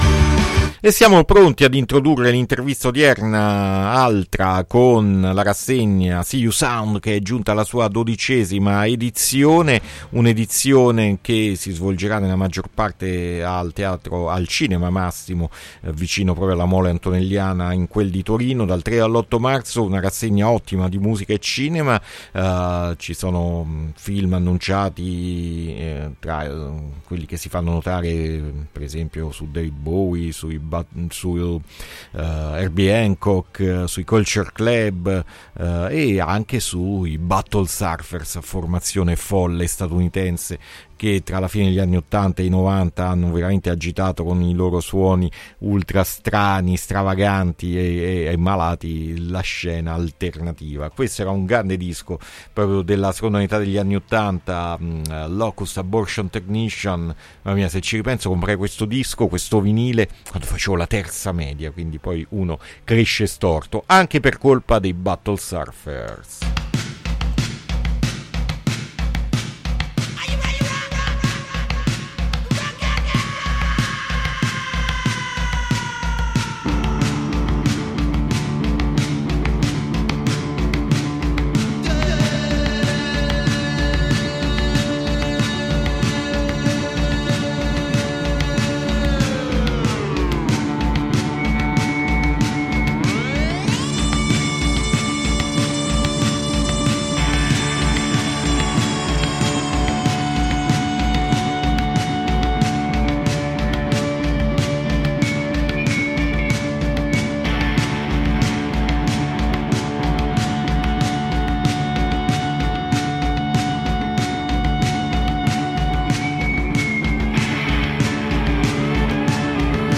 INTERVISTA SEEYOUSOUND A PUZZLE 2-3-2026